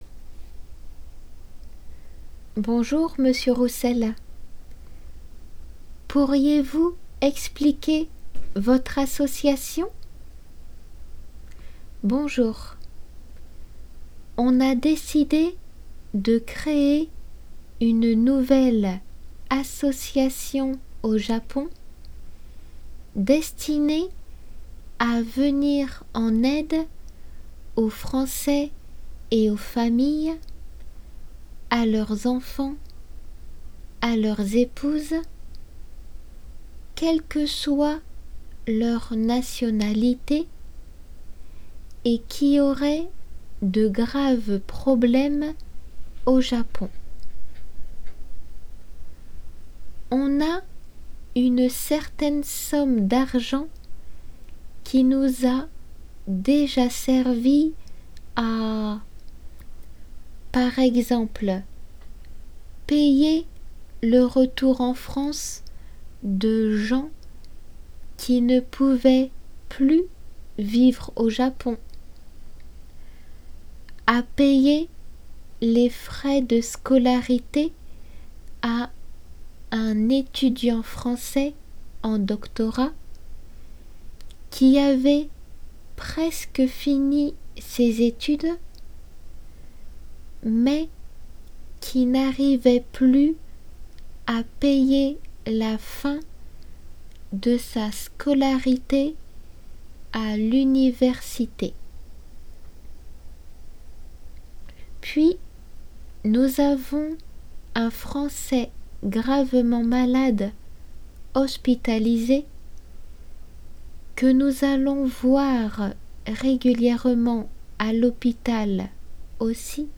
読まれるテキスト